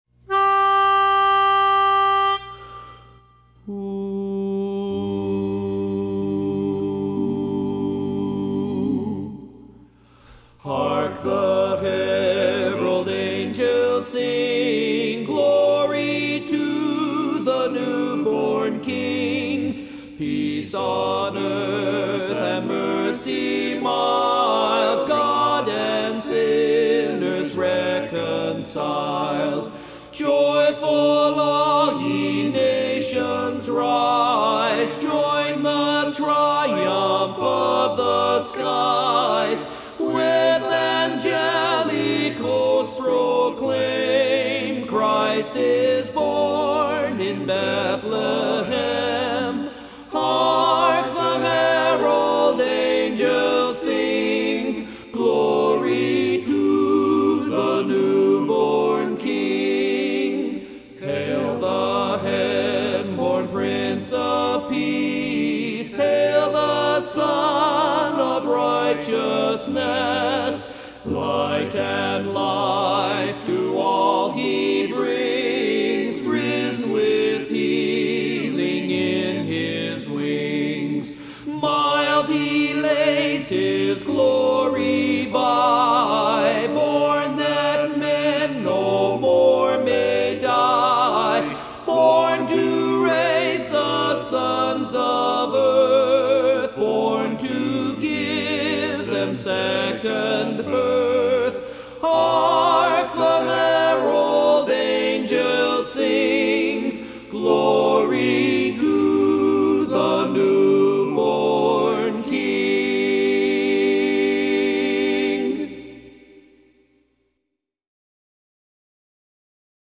Barbershop
Lead